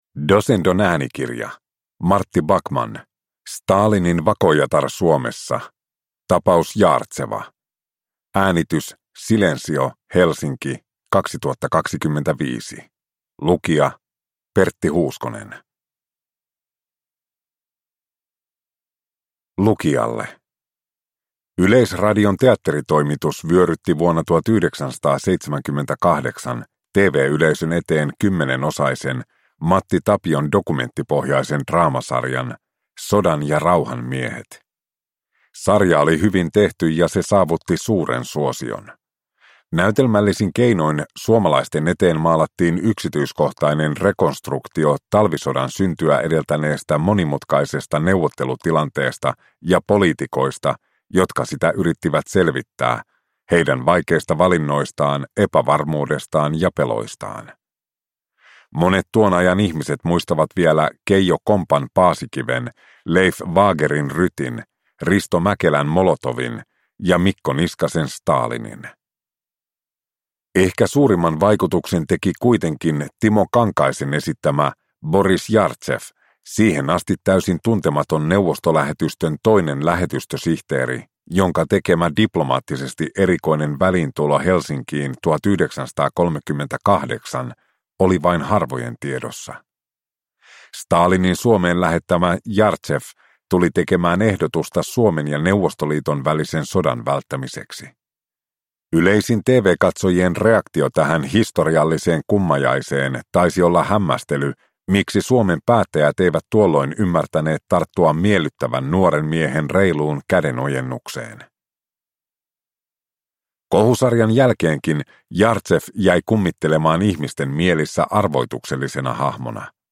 Stalinin vakoojatar Suomessa – Ljudbok